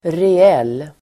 Uttal: [re'el:]